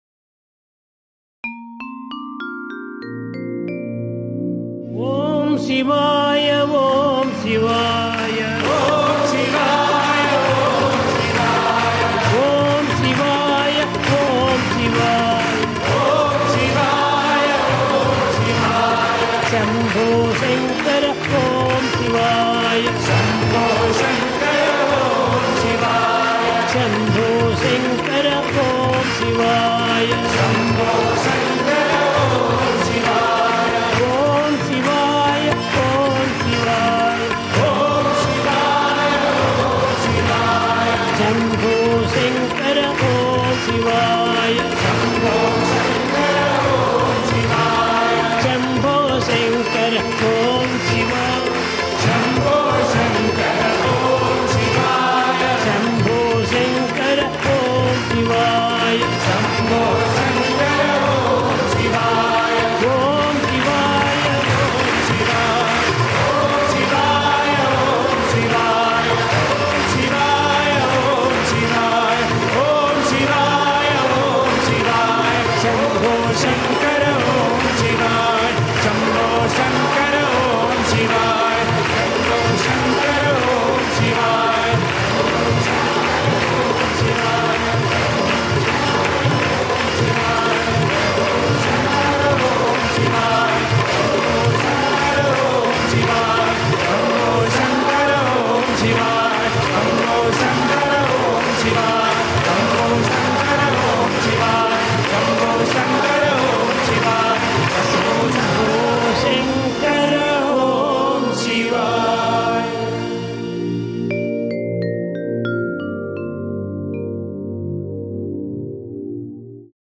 1. Devotional Songs
Mohanam / Bhoop
8 Beat / Keherwa / Adi
Fast